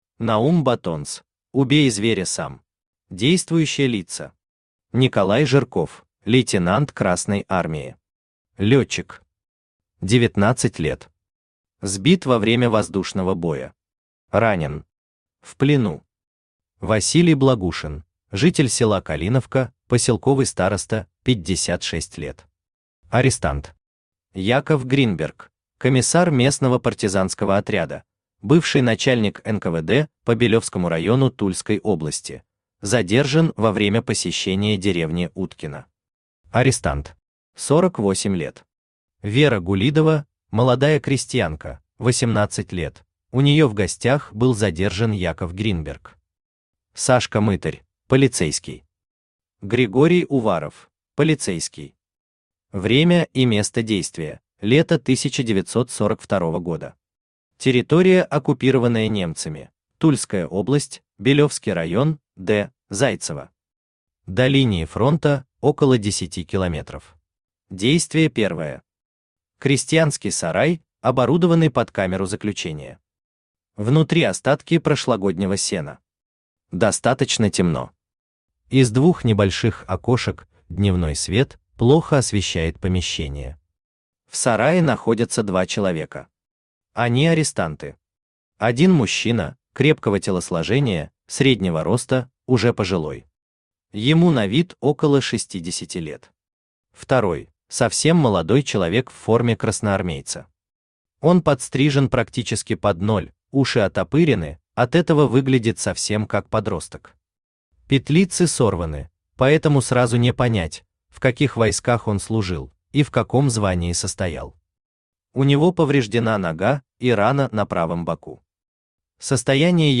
Автор Наум Баттонс Читает аудиокнигу Авточтец ЛитРес.